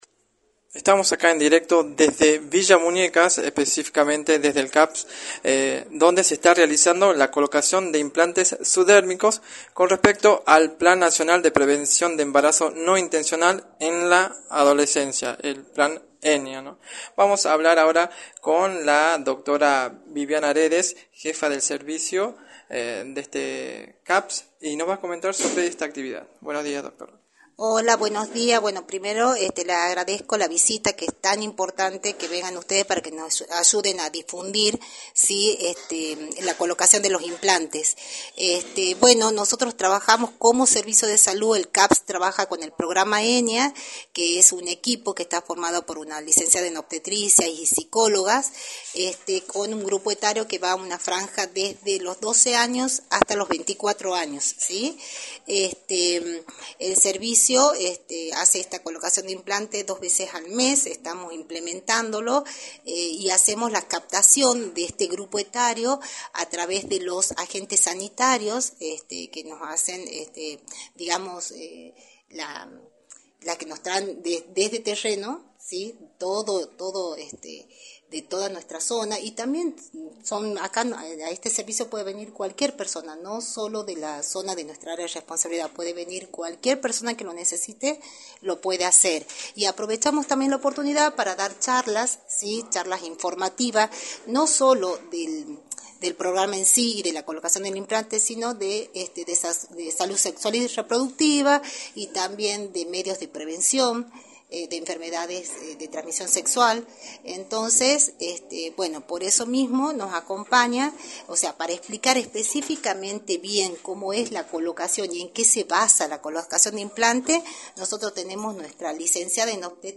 en entrevista para “La Mañana del Plata”